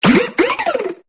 Cri de Maracachi dans Pokémon Épée et Bouclier.